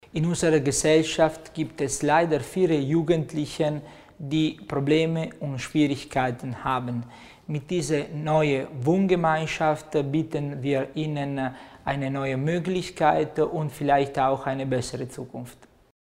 Landesrätin Stocker zur Bedeutung der sozialpädagogischen Arbeit bei Jugendlichen